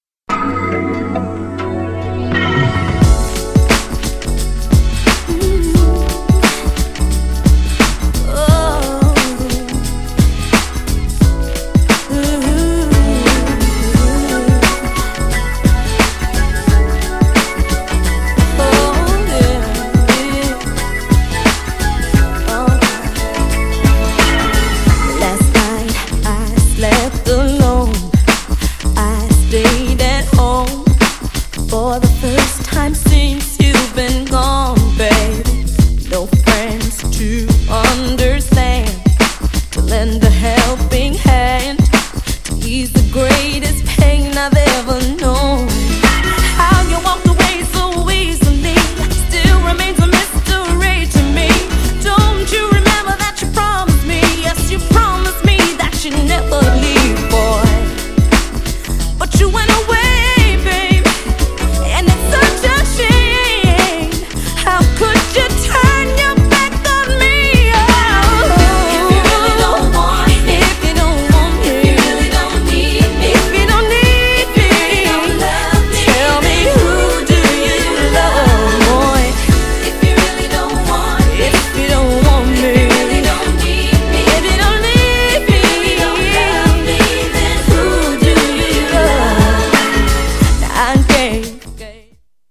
切ない女心を歌い上げる90's R&Bの典型ヒット。
GENRE House
BPM 121〜125BPM